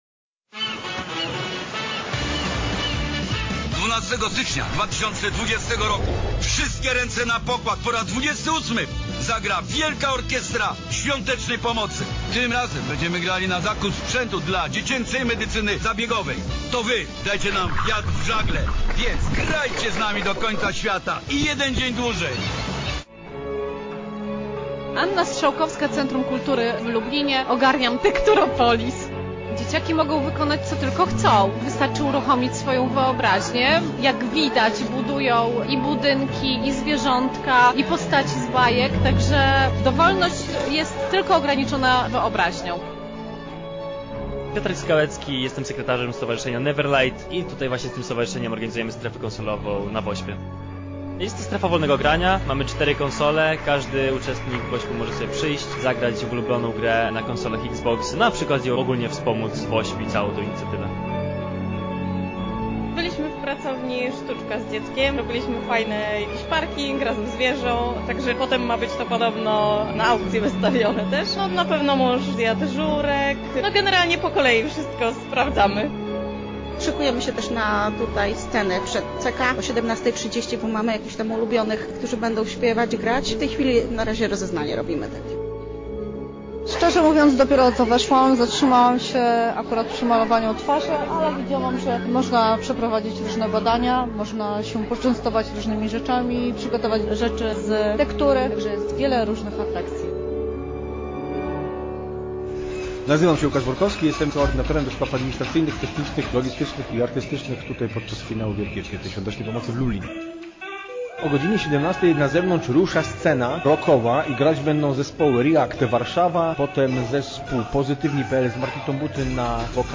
Na miejscu była nasza reporterka :